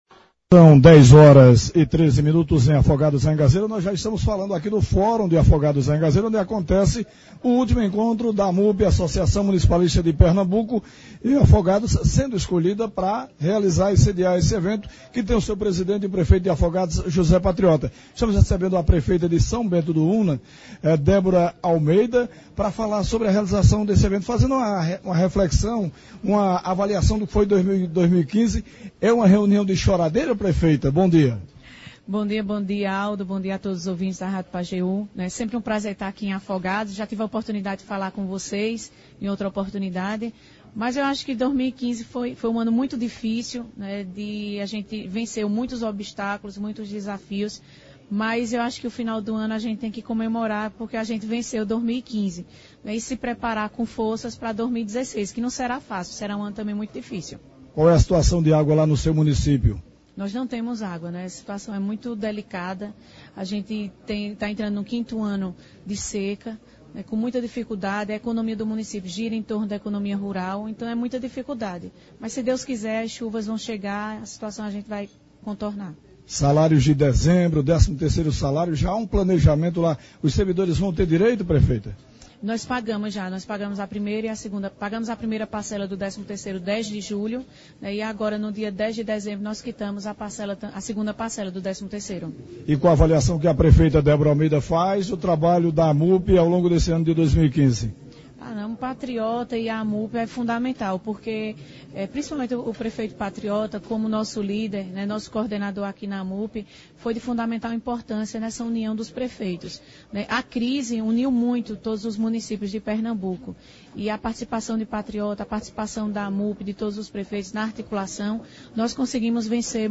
Na manhã de hoje (18) prefeitos de vários municípios pernambucanos se reuniram no Auditório do Fórum Laurindo Leandro Ramos em Afogados da Ingazeira-PE para a última Assembleia Geral da Amupe do ano de 2015.